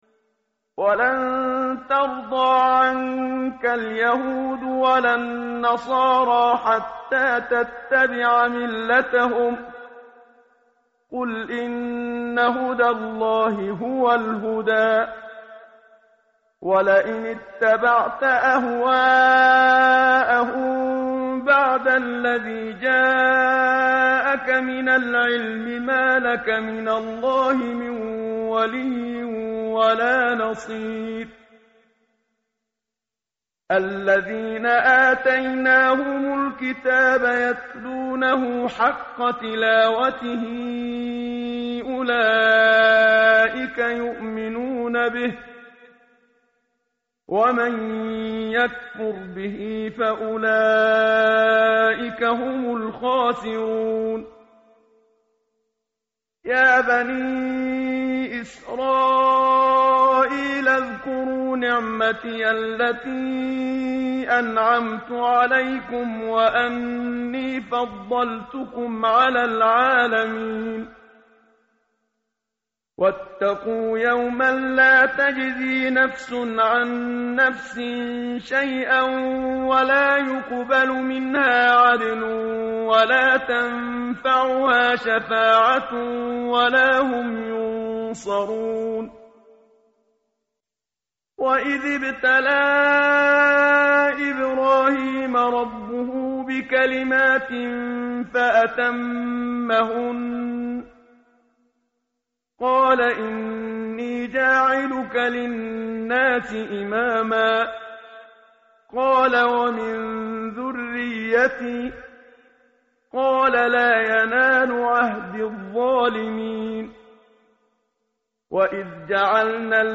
tartil_menshavi_page_019.mp3